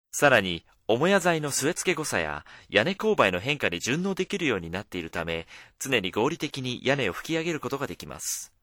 japanischer Sprecher für Werbung, internet, podcast, e-learning, Dokumentationen uva. Japanes voice over talent
Sprechprobe: Industrie (Muttersprache):
japanese male voice over artist